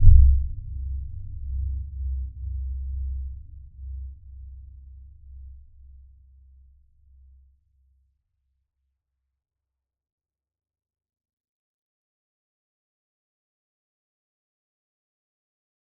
Dark-Soft-Impact-C2-f.wav